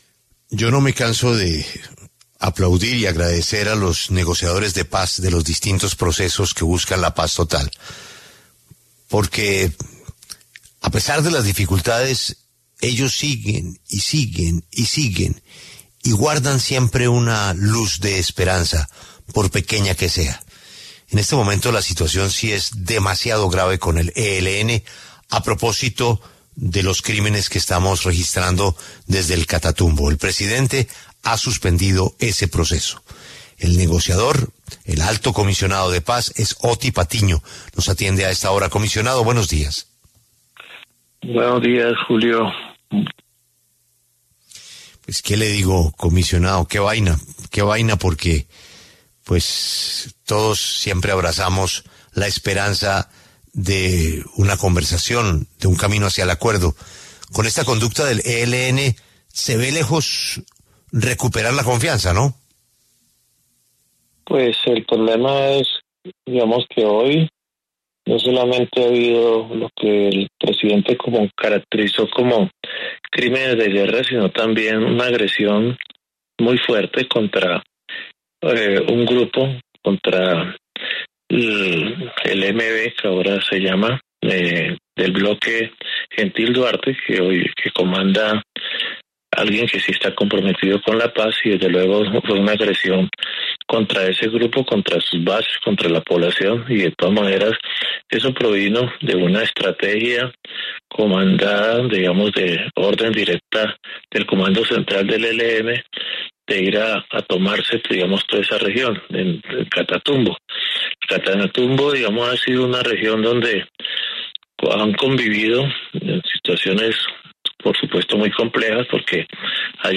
Otty Patiño, consejero comisionado de Paz, explicó en La W que la suspensión de los diálogos con el ELN responde a una estrategia militar deliberada del grupo en el Catatumbo, calificando los recientes ataques como crímenes de guerra.